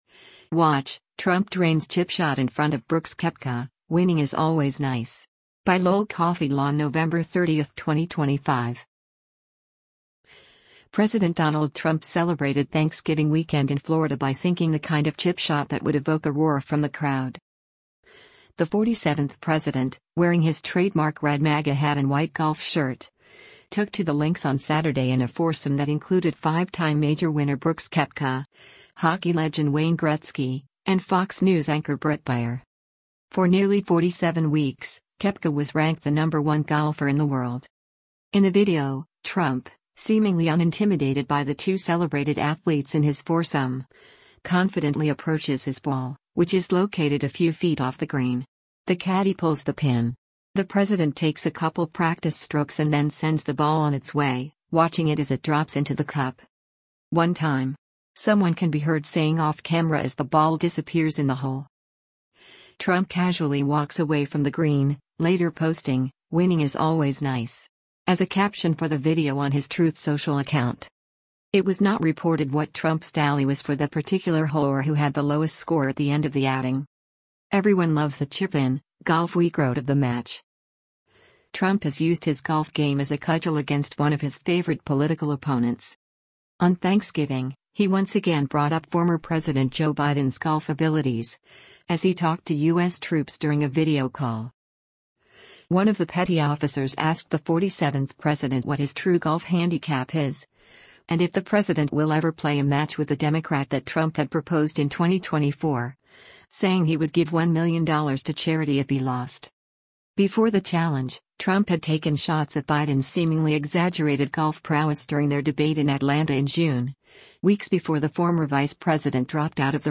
“One time!” someone can be heard saying off camera as the ball disappears in the hole.